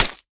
sounds / block / mud / break1.ogg
break1.ogg